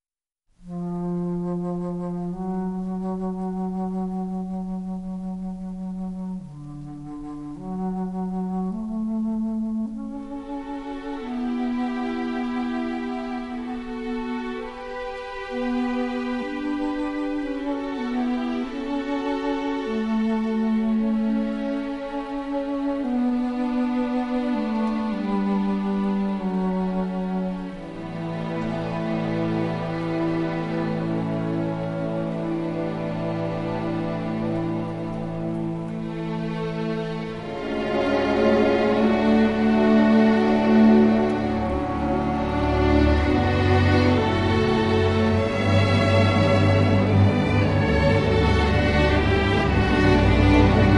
CD 1 Original Motion Picture Soundtrack